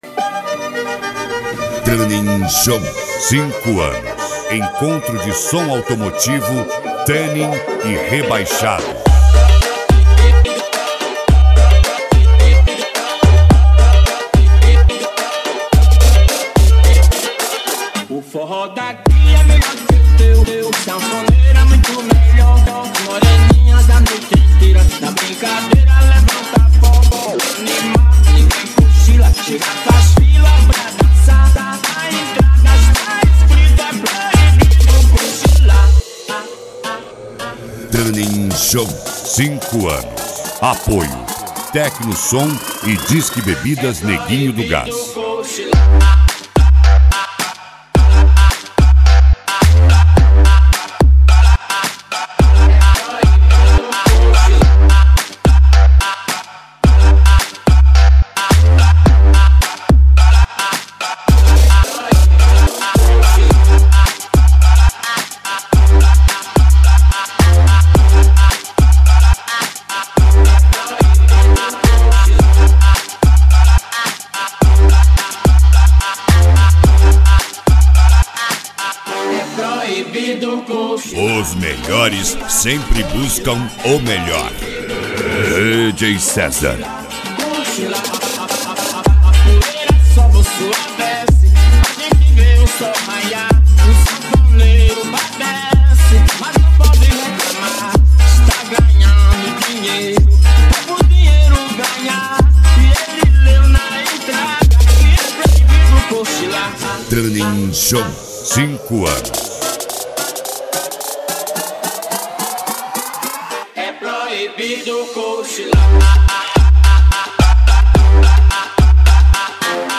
Mega Funk
Racha De Som